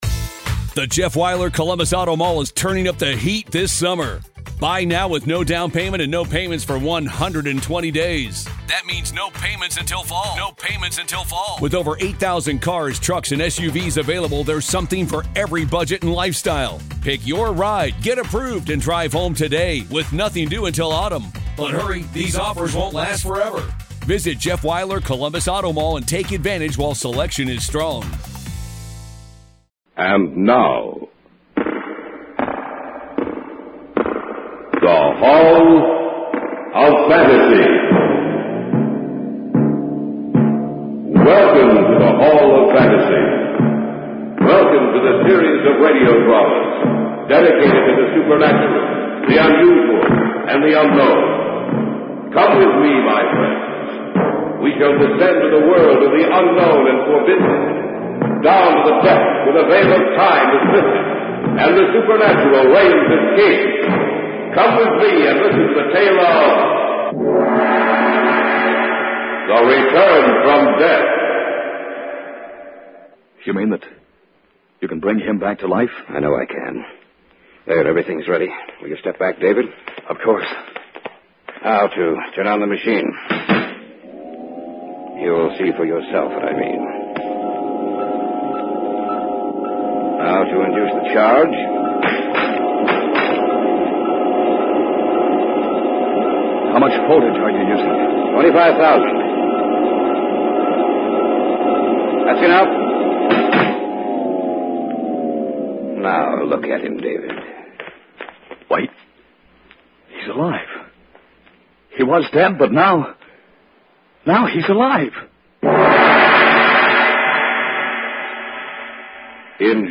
On this week's episode of the Old Time Radiocast we present you with two stories from the classic radio program The Hall of Fantasy.